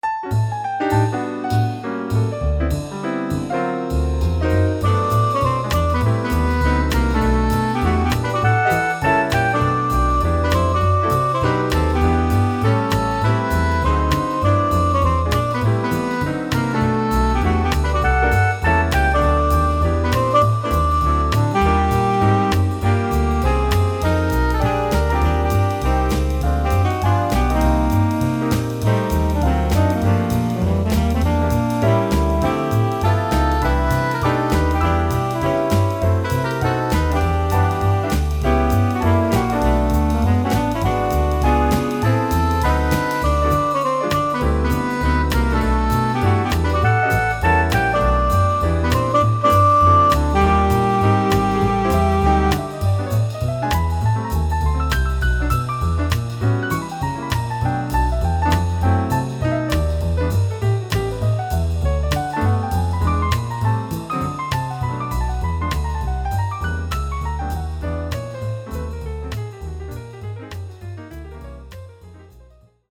Here are demo recordings I did at home of ten original pieces for jazz quintet. These are all short clips (1-2 minutes); the head with a chorus or two of piano solo.